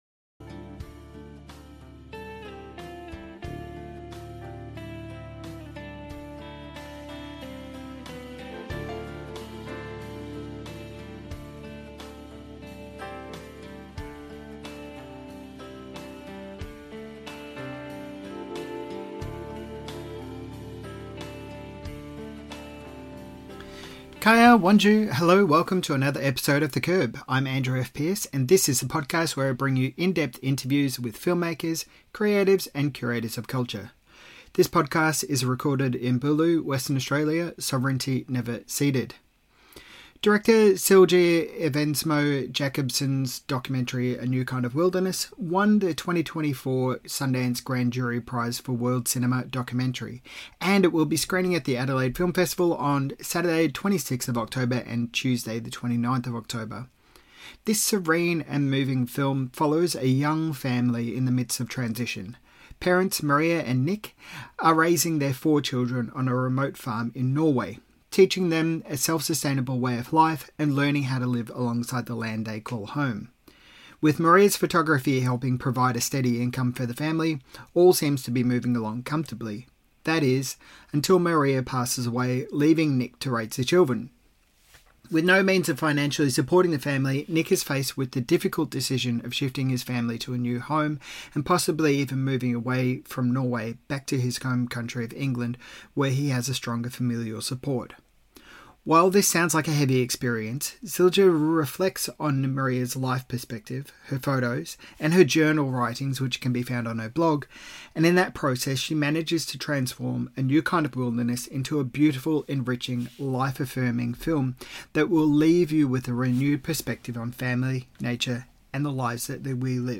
Adelaide Film Festival Interview